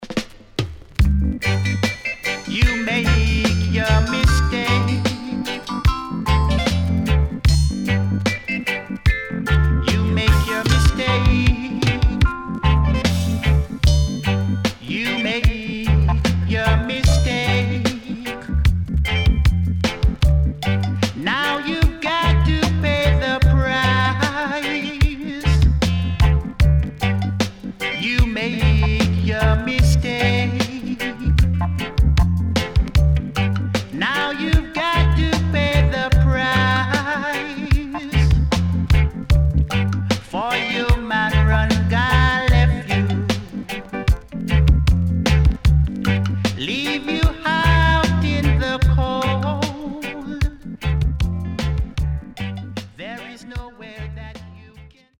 HOME > DISCO45 [DANCEHALL]  >  EARLY 80’s
SIDE A:プレス起因により少しチリノイズ入ります。